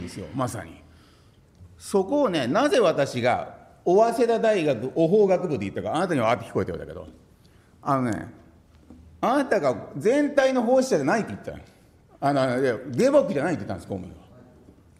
資料3　井桁議員が行った計4回の懲罰に対する弁明　音声①　（音声・音楽：180KB）